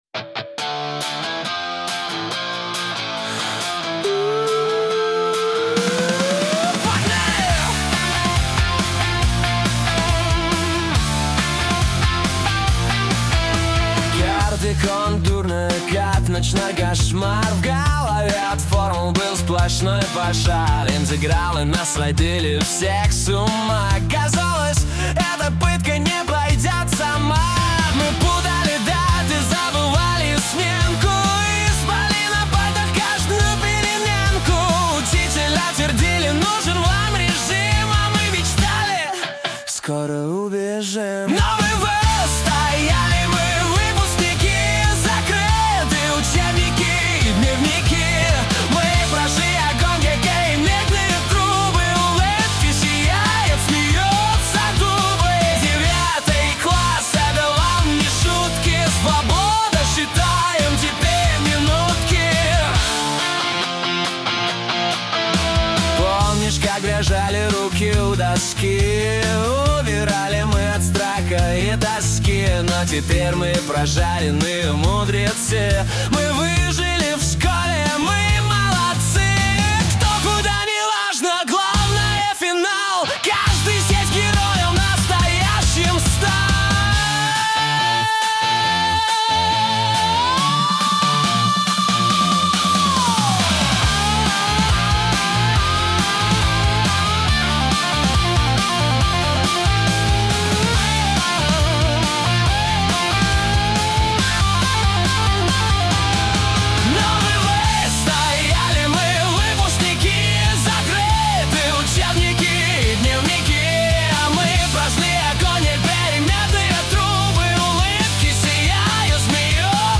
энергичный pop-rock стиль с живыми гитарами.